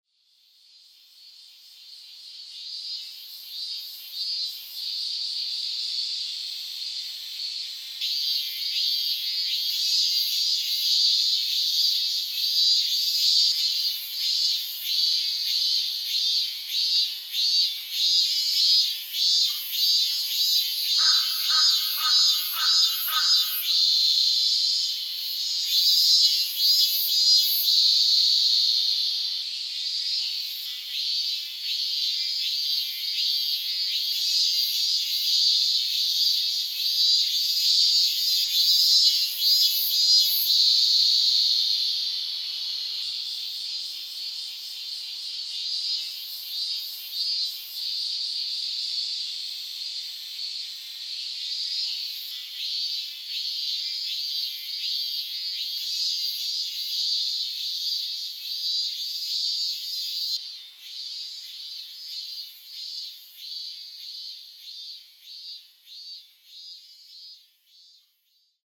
自宅の近所で、平日の早朝に録音したミンミンゼミの声です。
最初の方に少しクマゼミの声も聞こえて賑やかです。